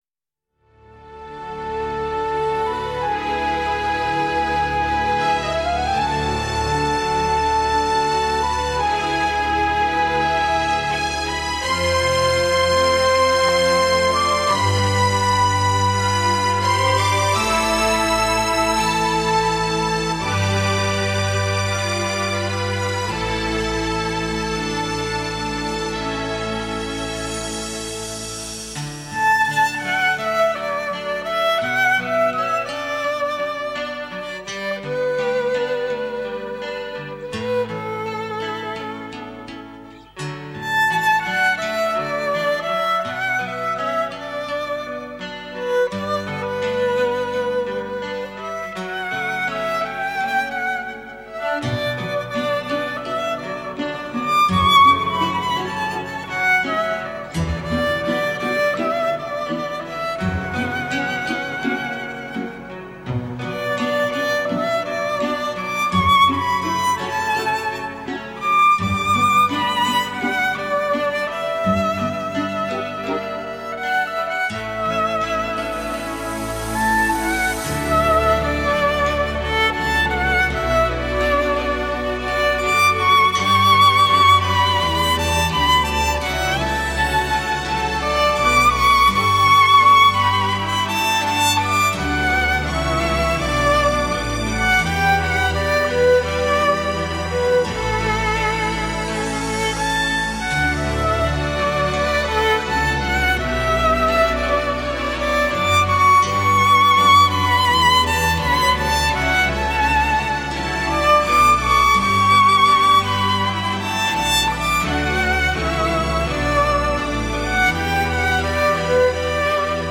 Craceful Violin